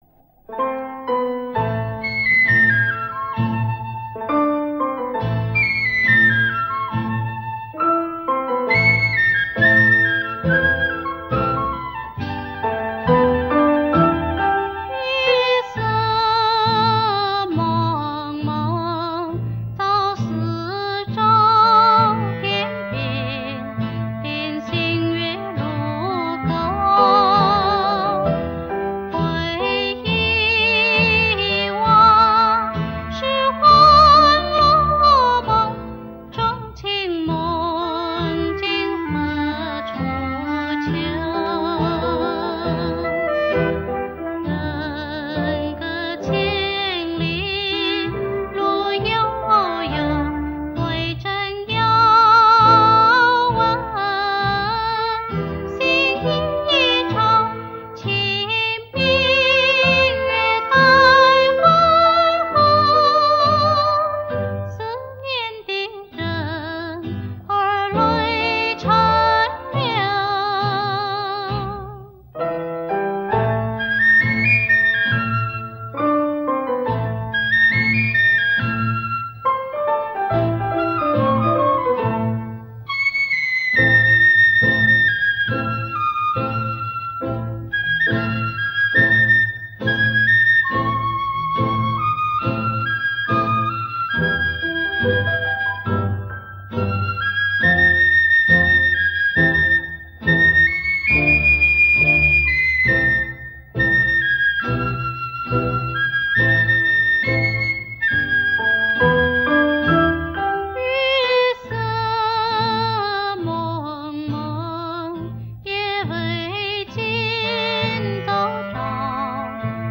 第一部分是“专辑部分”，音质比较好，